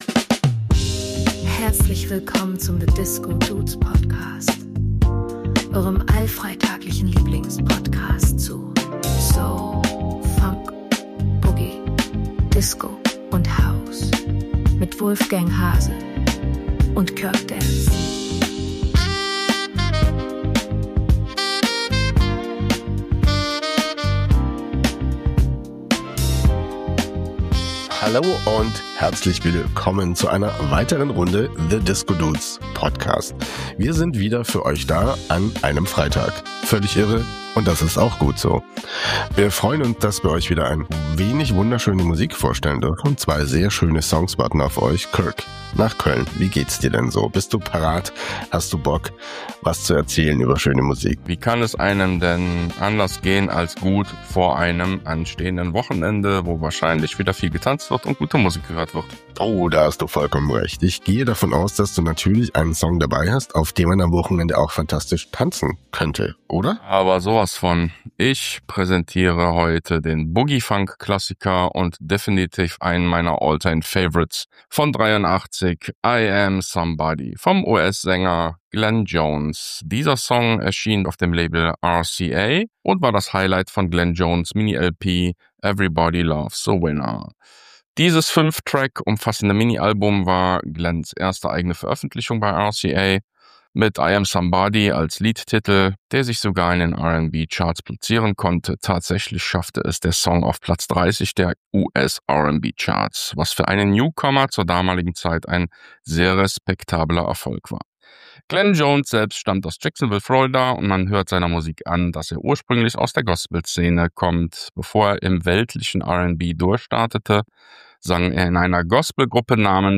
Gospel to 🪩 Disco und Italo Sound